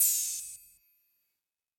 MB Open Hat (7).wav